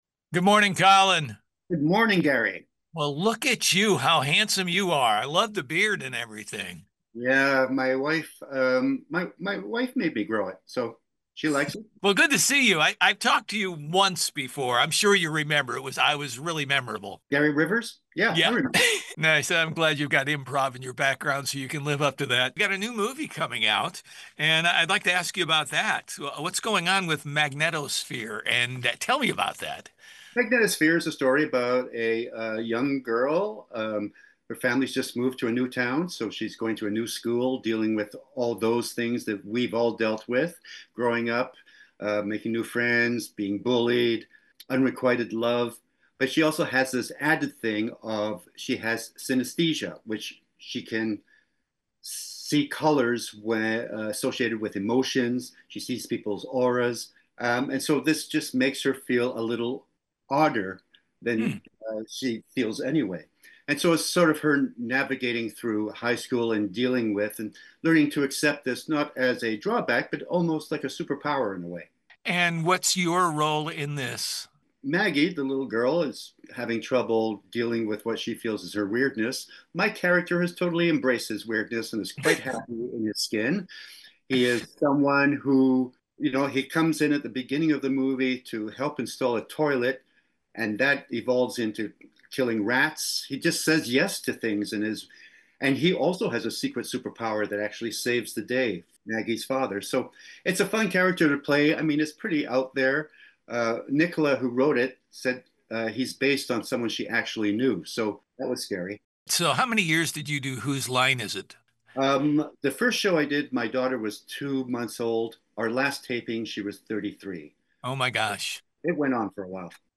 COLIN_mixdownRADIOEDIT.mp3